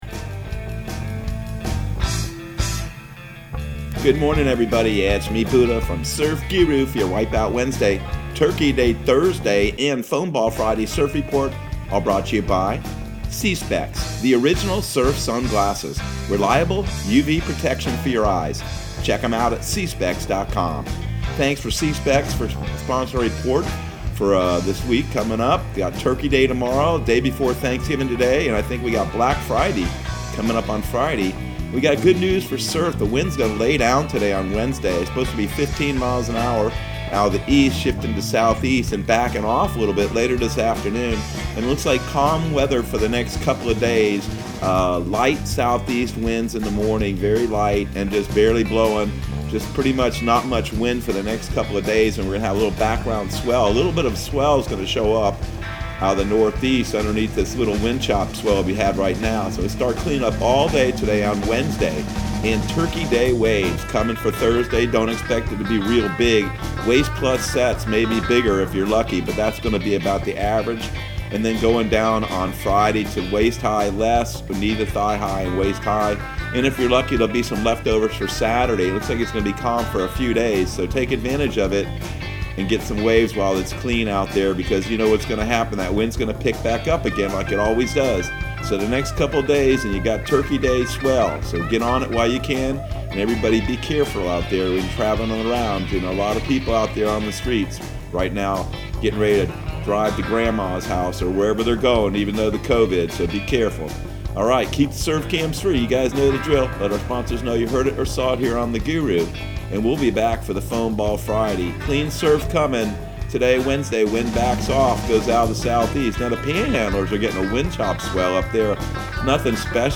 Surf Guru Surf Report and Forecast 11/25/2020 Audio surf report and surf forecast on November 25 for Central Florida and the Southeast.